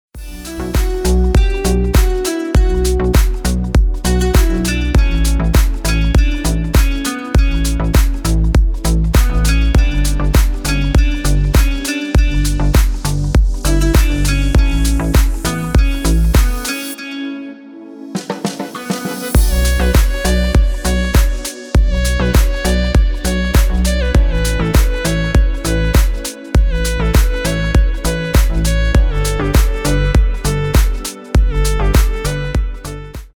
танцевальные
восточные